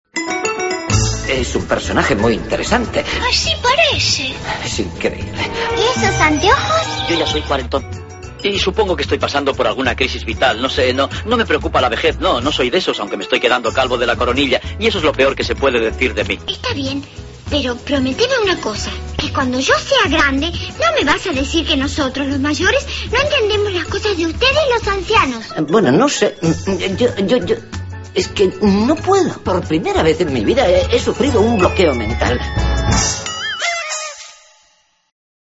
Ficción radiofónica; la primera conversación entre los dos iconos de bronce, el Woody Allen y Mafalda, en Oviedo. Se dan la bienvenida puesto que a partir de hoy son vecinos en el centro de la capital asturiana.